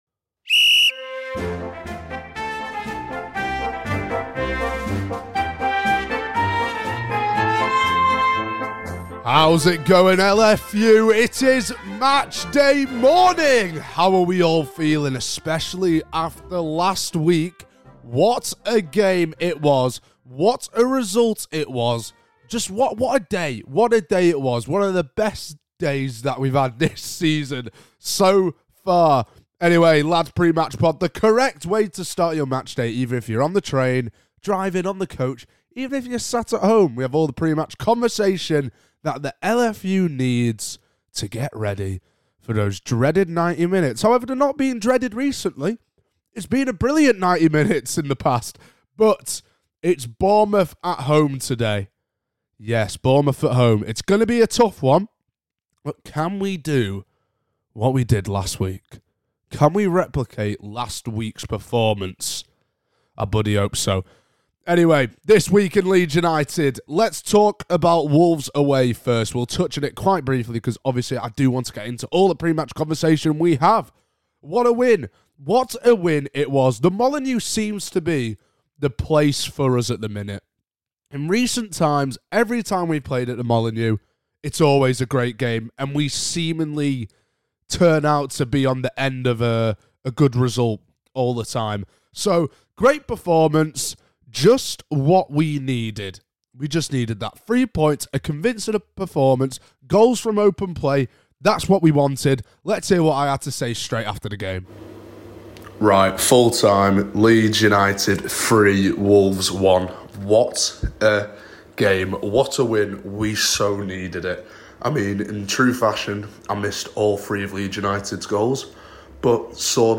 L2L IS BACK but no Dad just Lad this time…